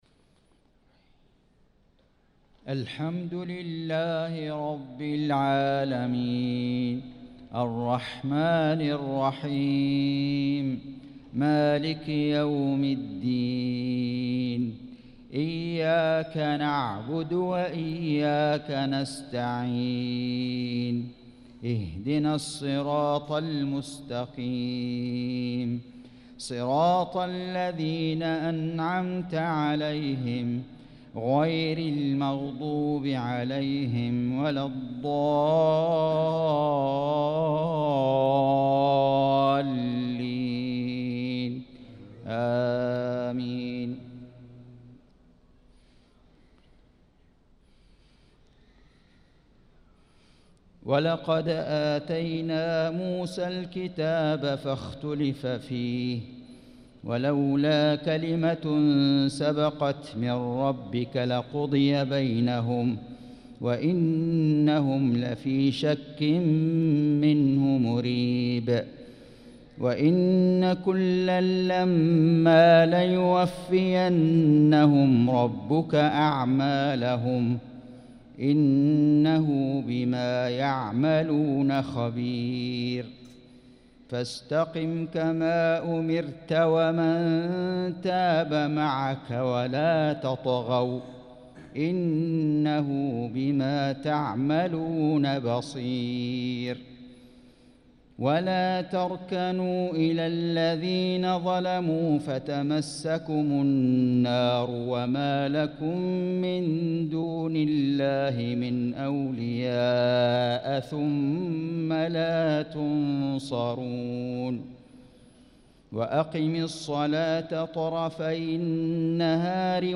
صلاة العشاء للقارئ فيصل غزاوي 4 شوال 1445 هـ
تِلَاوَات الْحَرَمَيْن .